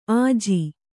♪ āji